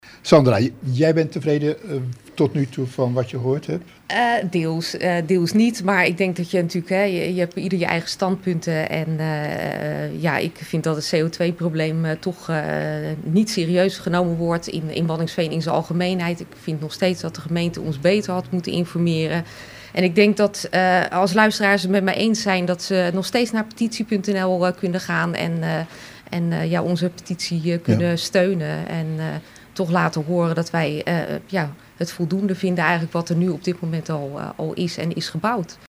Interview bij RTW over biomassacentrales